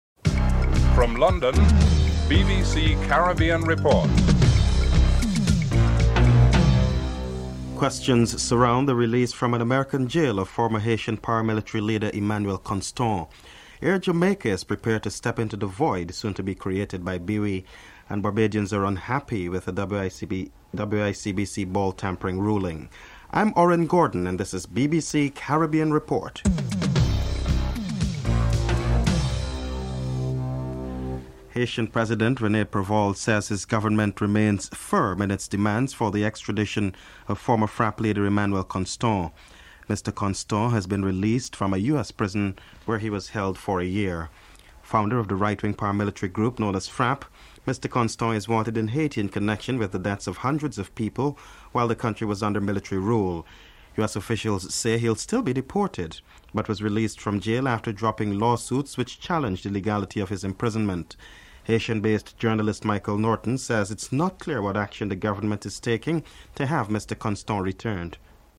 The British Broadcasting Corporation
1. Headlines (00:00-00:30)